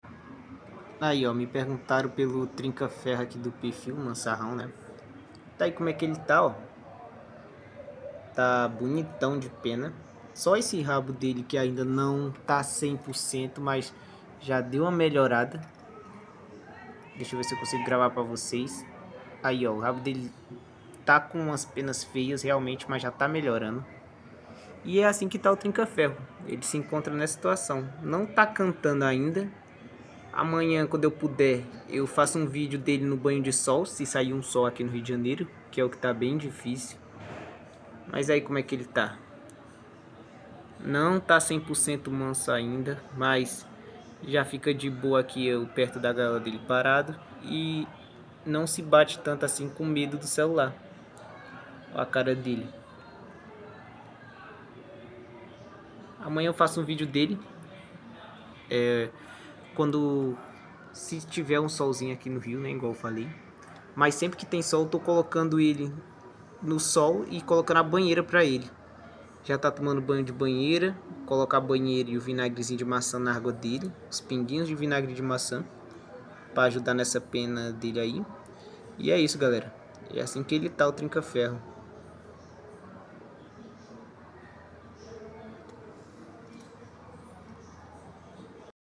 Atualização do trinca ferro mansarrão sound effects free download